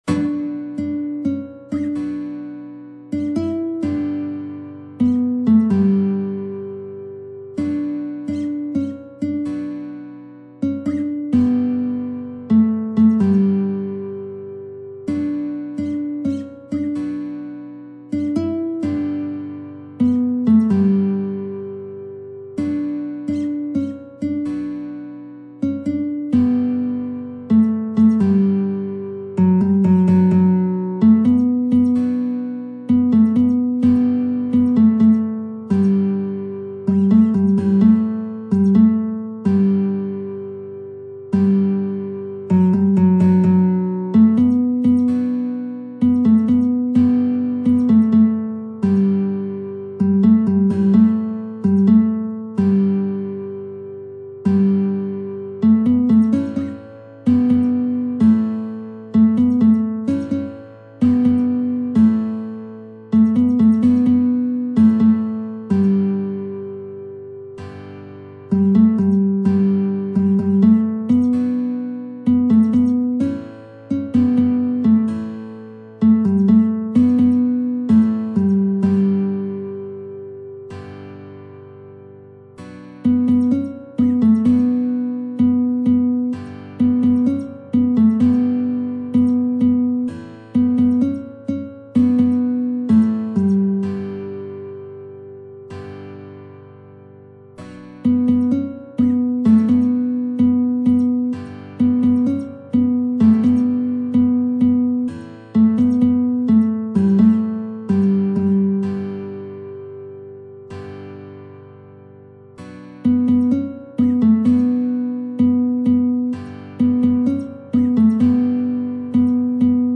نت ملودی تبلچر و آکورد